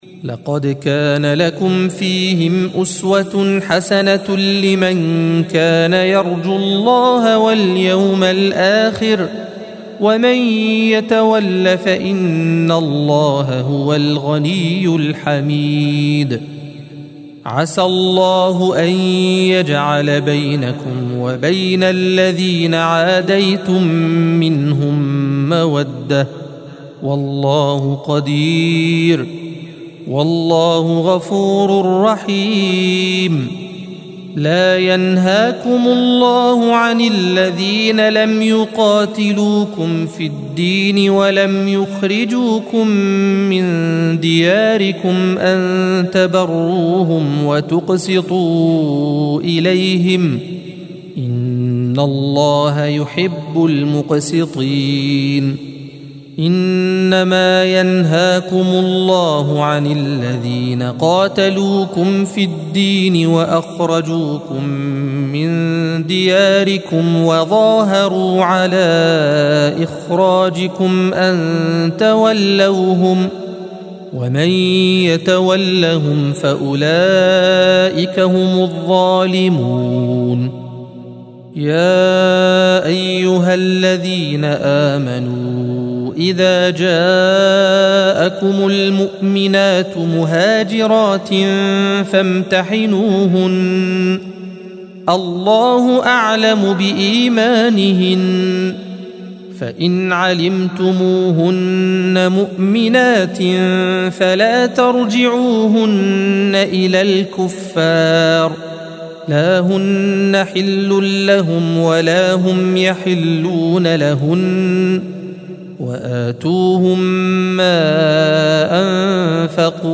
الصفحة 550 - القارئ